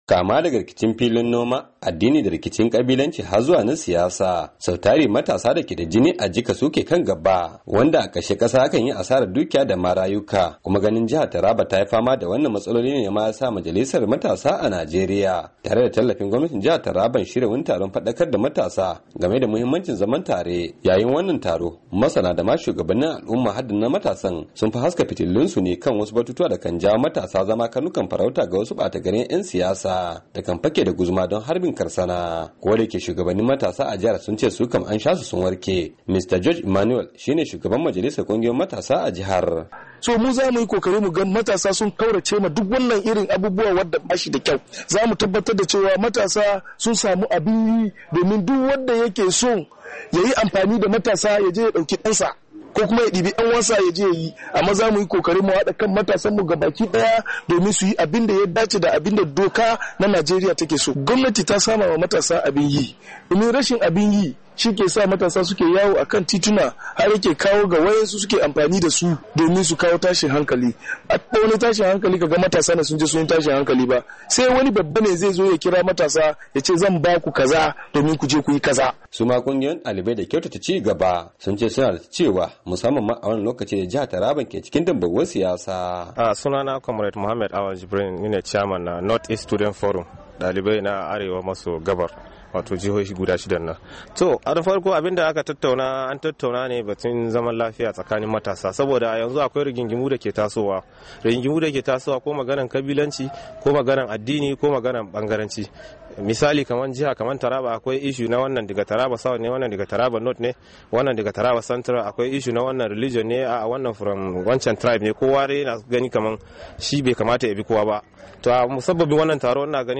Rahoton